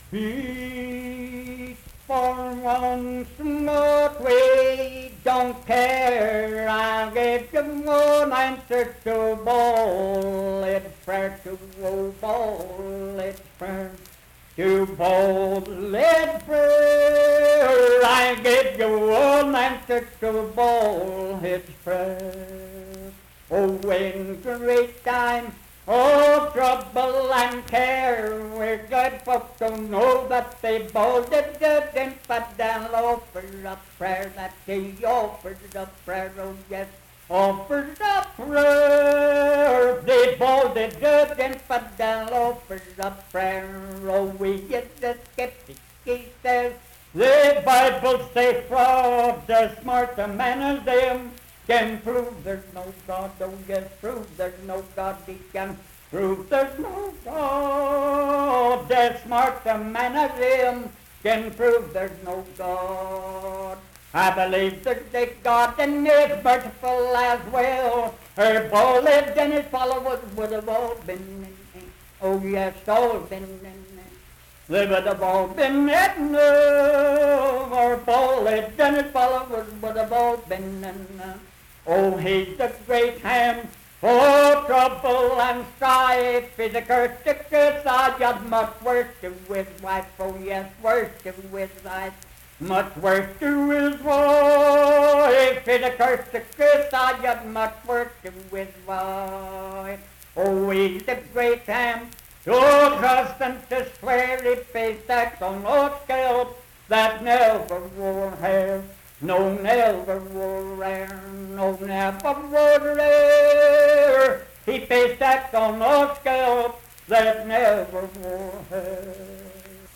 Unaccompanied vocal music performance
Verse-refrain 14(4w/R).
Voice (sung)